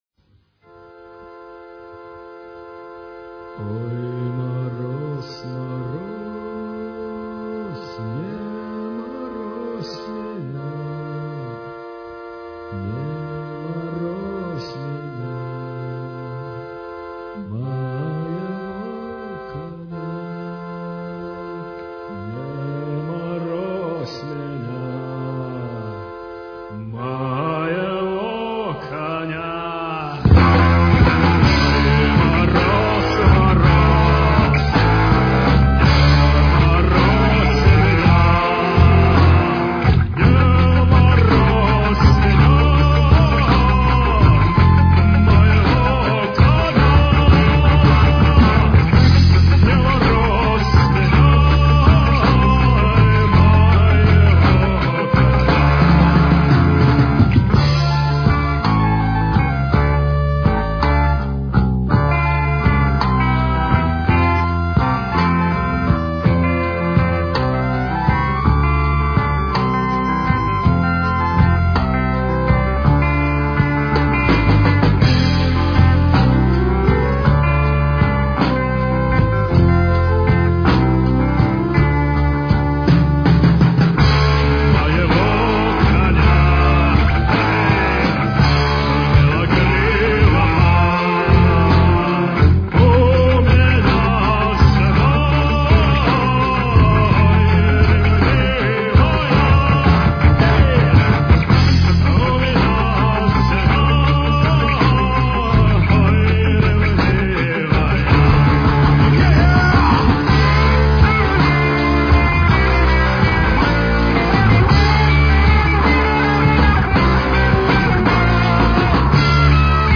Рок-Панк-Шарм-Попс-группы
бас, вокал
клавиши, гитара
ударные
Запись сделана в подвале Института в феврале 1995 года...